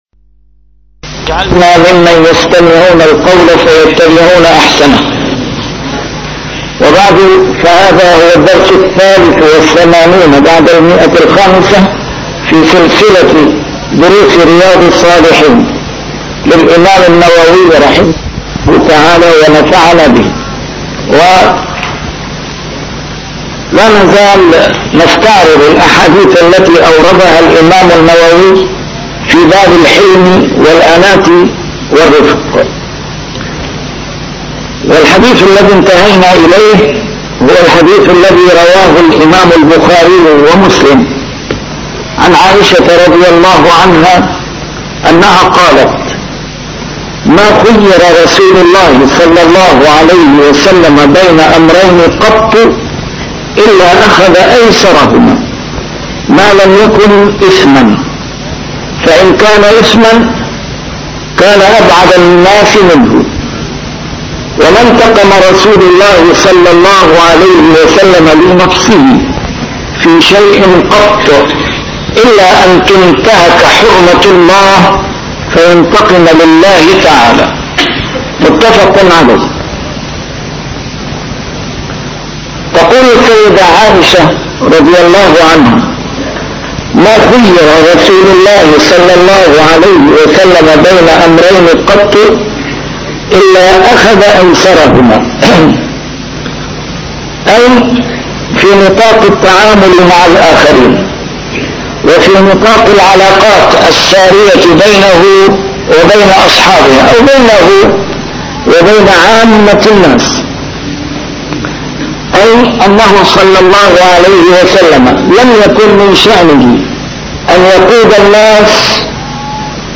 A MARTYR SCHOLAR: IMAM MUHAMMAD SAEED RAMADAN AL-BOUTI - الدروس العلمية - شرح كتاب رياض الصالحين - 583- شرح رياض الصالحين: الحلم والأناة والرفق